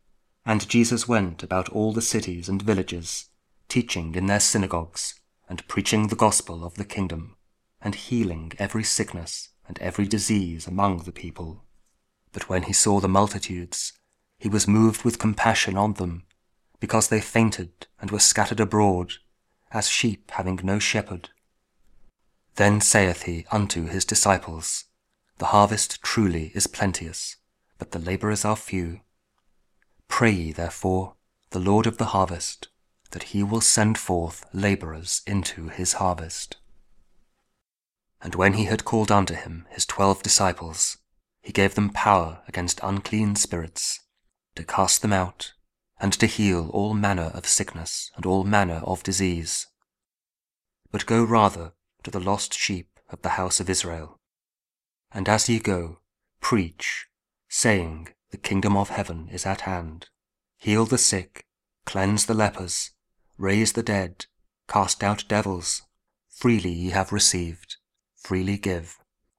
Matthew 9: 35 | King James Audio Bible | Saturday, Advent Week 1